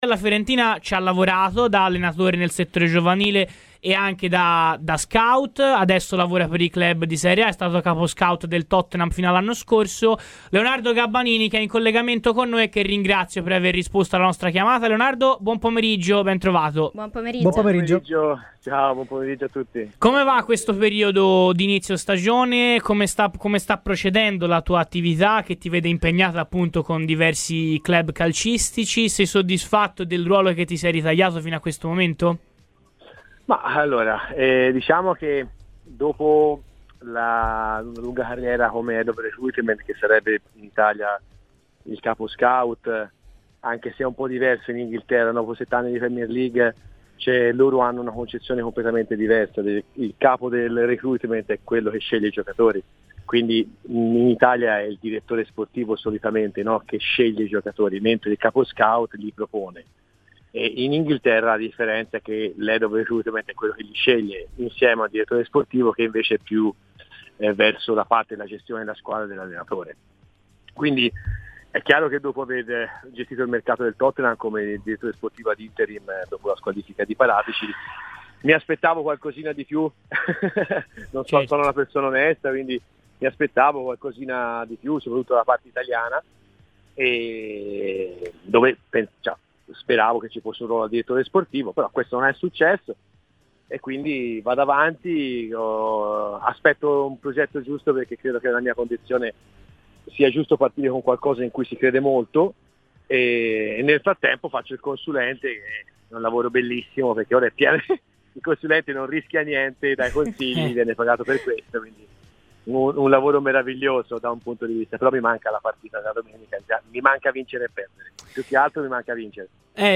Ascolta il podcast per l’intervista integrale!